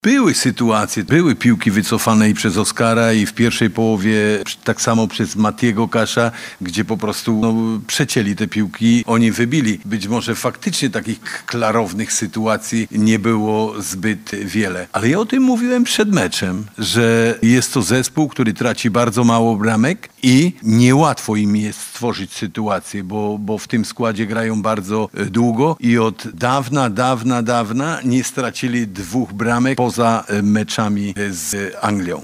– Mówił Jan Urban o małej liczbie sytuacji pod bramką